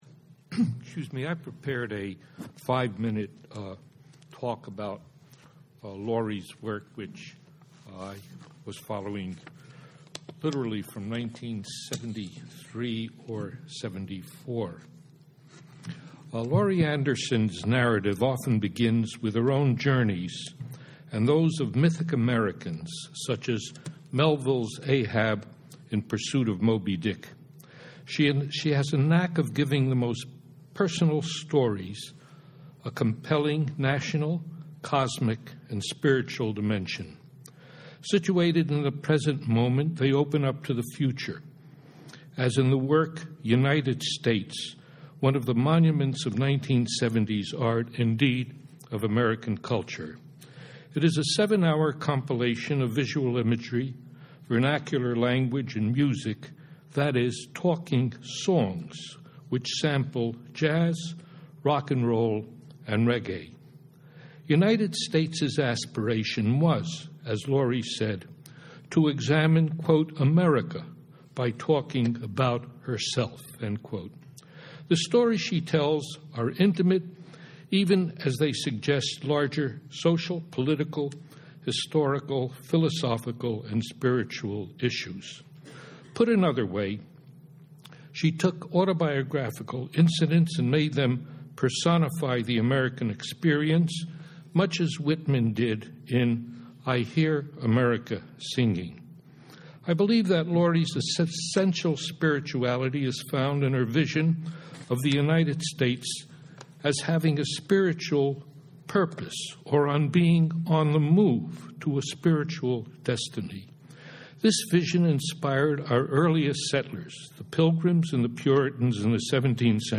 Listen to how Laurie Anderson navigated her way through the sometimes elusive / dim / intangible subjects of spirituality, faith and religion in a public conversation held at Columbia University on February 10, 2011 - thanks to the CU Institute for Religion, Culture & Public Life website , the conversation can be downloaded as a podcast : ' Refiguring the Spiritual' with Laurie Anderson (audio length: 61 mins 35 secs, file size: 28.1 MB) It's almost impossible (and unnecessary) to list all the topics touched upon - just to name a few: Melville and the Biblical symbolism of Moby Dick; silence, pain and meditation; being a spy as an artist, Laurie's pentecostal missionary grandmother, Capitalist Realism , making freeform fiction out of Egyptian architecture; John Cage, NASA's poetic projects, pressure of technology; pitch and prayer; How to Be Idle , the School of Life , what is an artist, etc. etc. etc.
IRCPL-Anderson_panel_qa.mp3